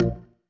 GAR Organ Bb.wav